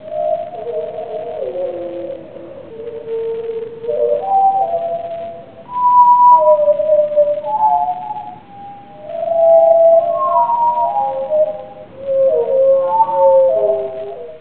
(All of these samples are 8-bit, sorry.)
Thrush
The thrush page presents some interesting slowed-down 2-part harmony thrush sounds, apparently produced by a single individual.
Thrush recording [.wav]
thrush.wav